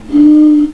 Blown bottle #1